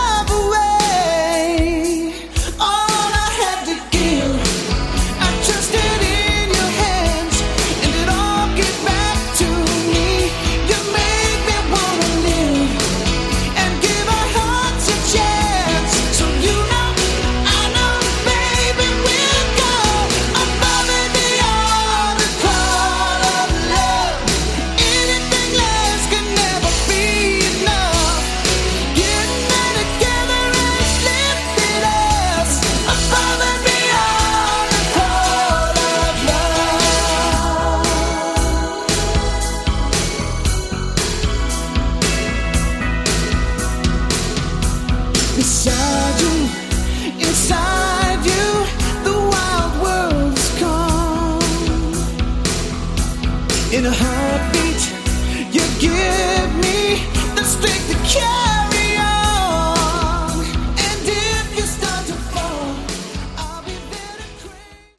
Category: Lite Aor